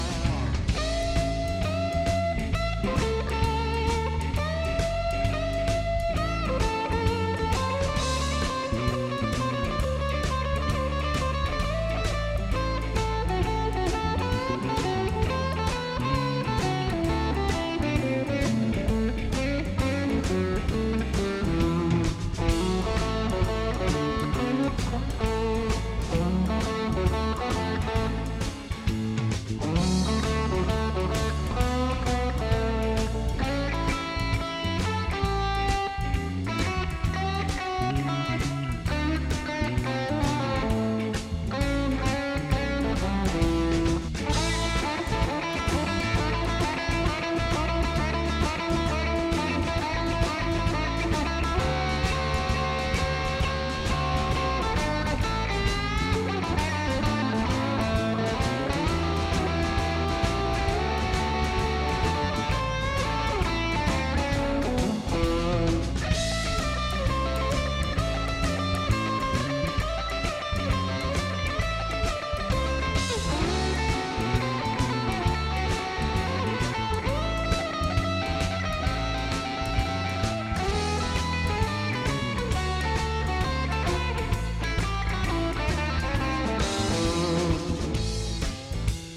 Eingespielt wieder nur am PC über Gearbox ins Audacity, wieder mit bisserl Reverb und Delay und diesmal auch Verzerrer. Gitarre war diesmal die hier: Fender Vintera '70s Telecaster Thinline (aber nicht die US-Version ), PUs in Mittelstellung, am Ende nur Steg. Sorry, war etwas im "Rock'n'Roll"-Modus und hab nicht mehr mitgezählt Neue Liste: x 1.